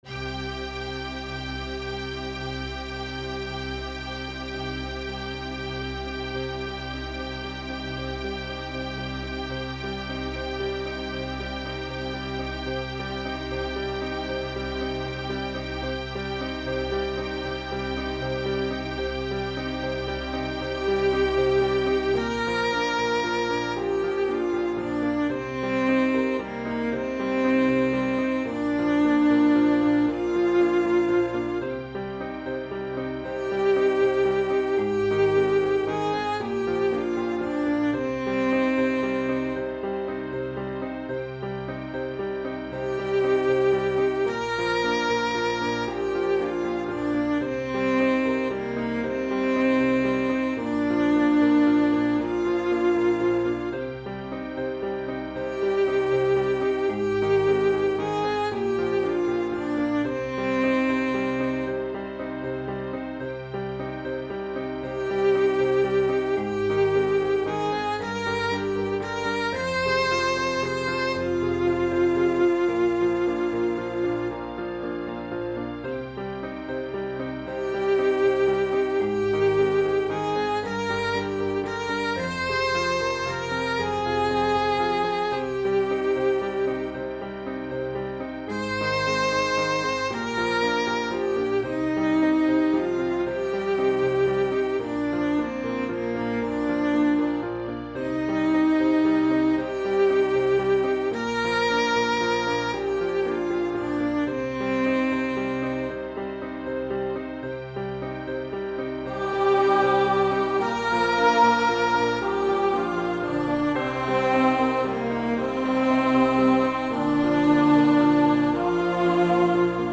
Voicing/Instrumentation: Choir Unison , Organ/Organ Accompaniment